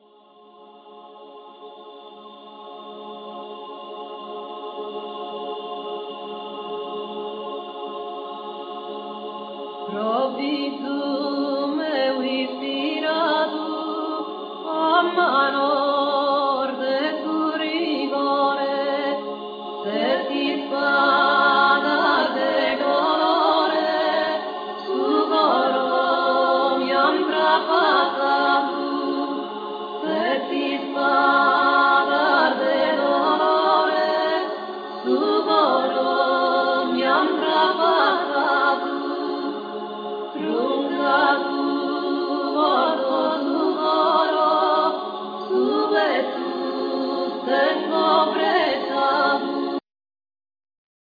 Vocal
Solo guitar,Harp,Mandola
Acoustic guitar
Keyboards
Double bass
Flute,Bottles
Percussions
Alto & soprano saxes
Melodeon